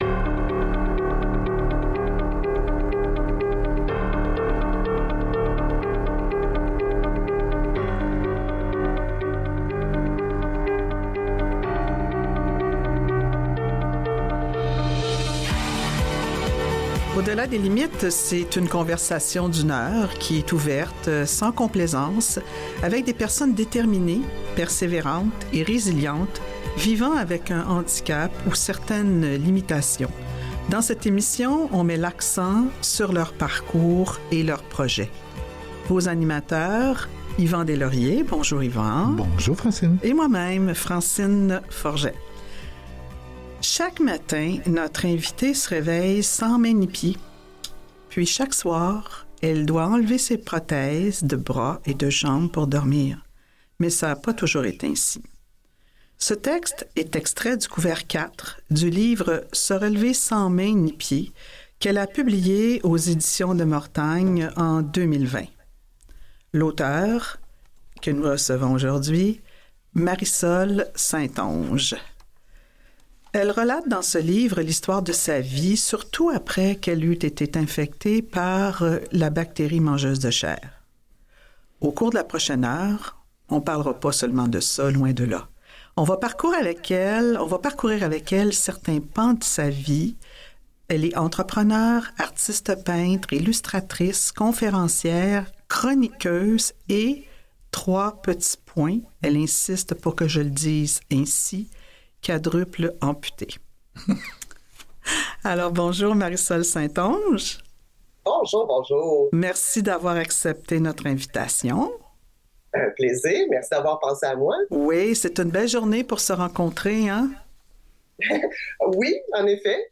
Au-delà des limites : une conversation d’une heure ouverte et sans complaisance avec des personnes déterminées, persévérantes et résilientes vivant avec un handicap ou certaines limites.